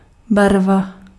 Ääntäminen
IPA : /peɪnt/